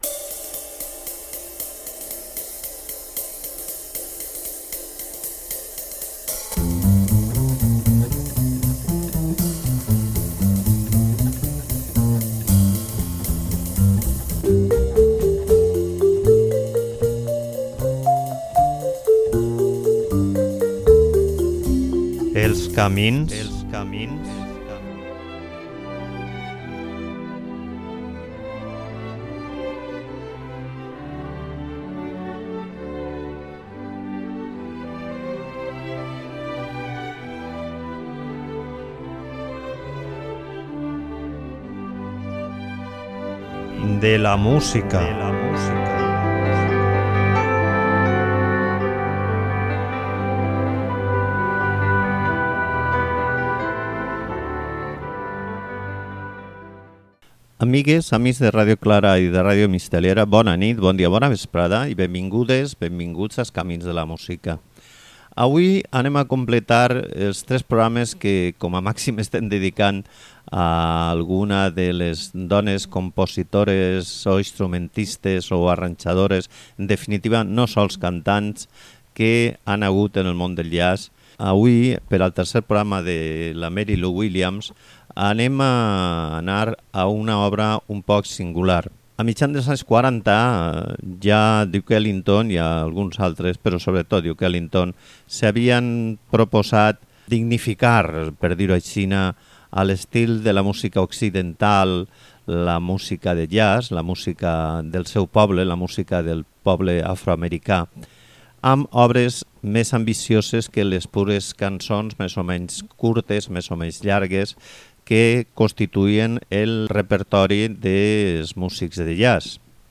L'audició de la suite acompanyada en format de trio va a ser la música que escoltareu huí.